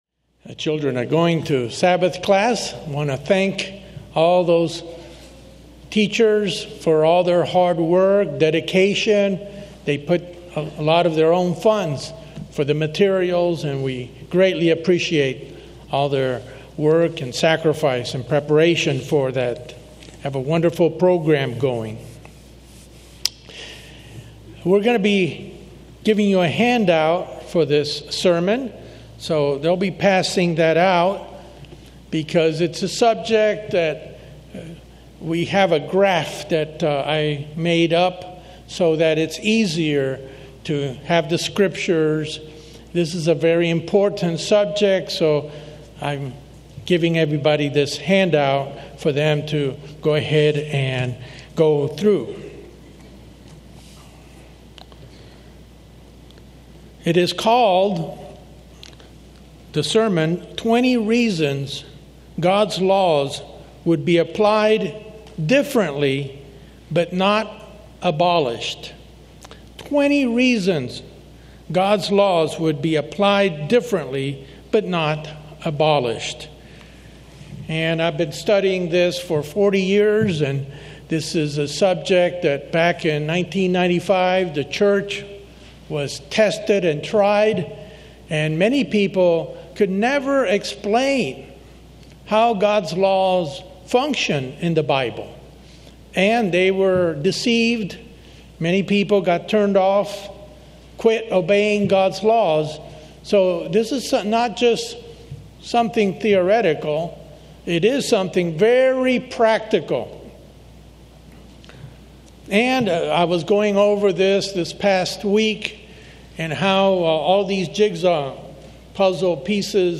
This first of a two-part sermon explains the basic reasons why certain laws were made by God and why they are not to be abolished, as some have declared.